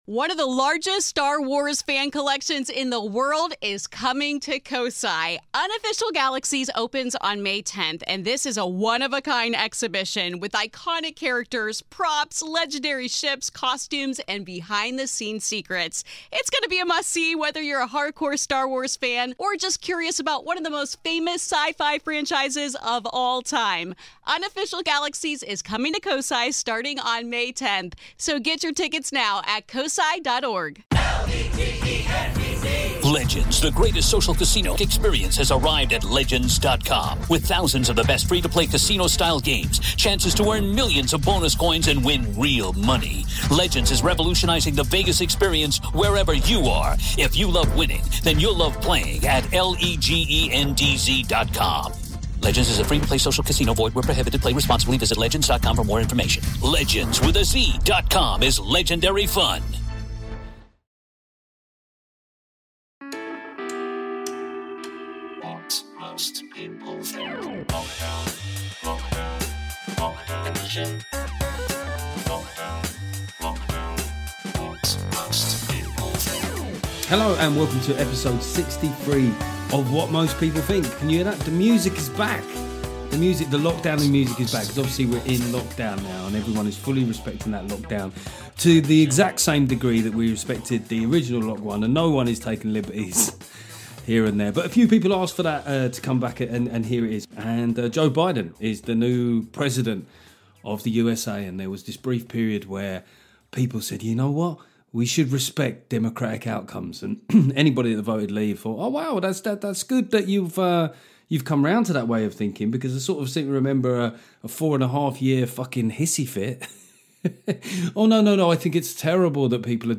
After we catch up on some of the hysterical and hypocritical nonsense coming out of Biden's victory, I have a long chat with brilliant comedian and Libertarian Dominic Frisby.